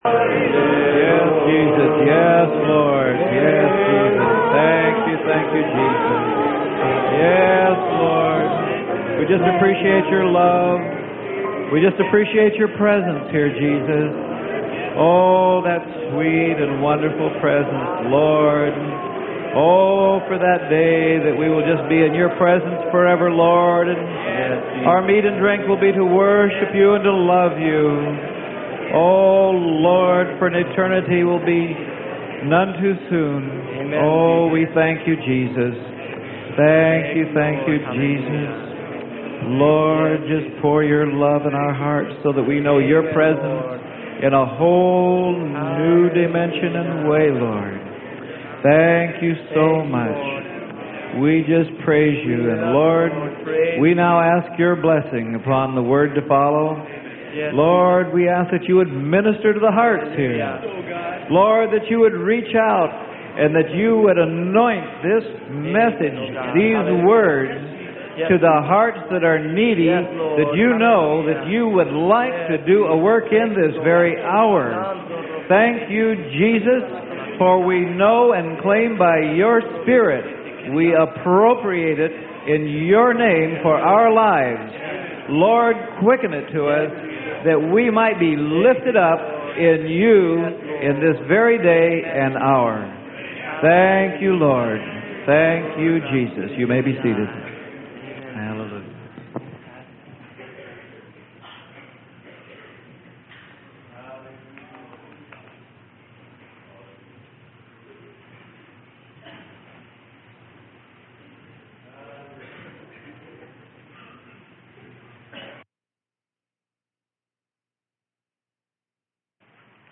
Sermon: HE IS ABLE TO PERFECT THAT WORK.